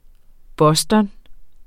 Udtale [ ˈbʌsdʌn ]